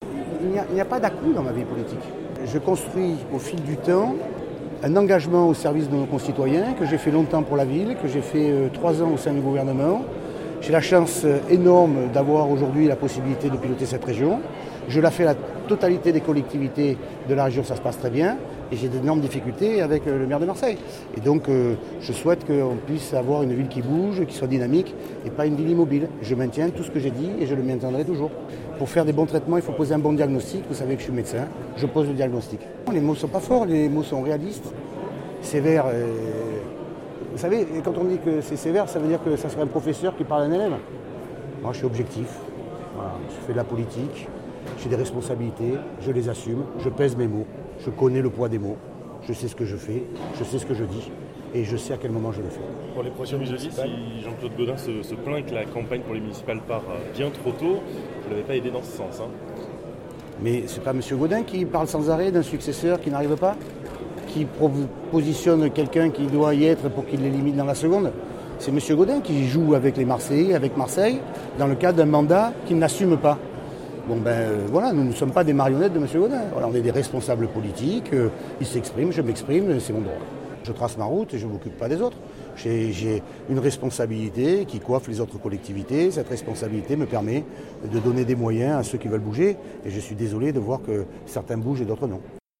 Les journalistes étaient présents en nombre pour les vœux à la presse de Renaud Muselier.